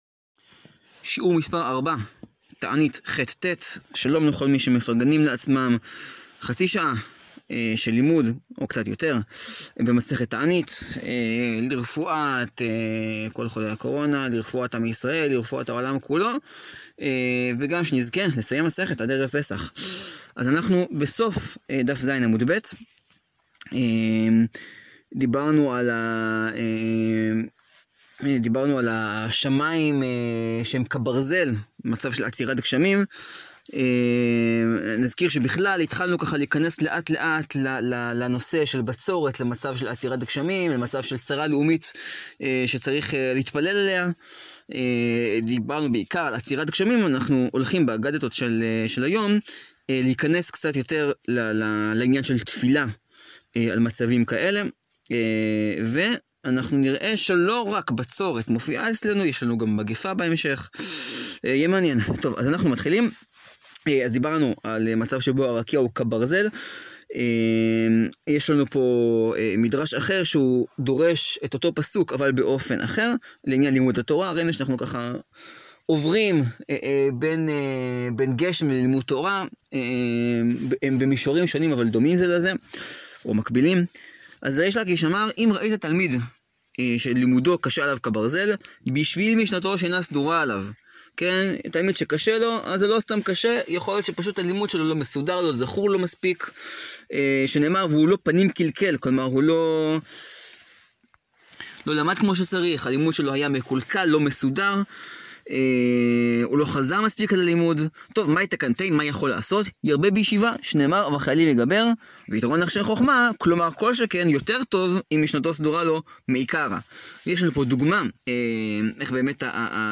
שיעור 4 להאזנה: מסכת תענית, דפים ח-ט.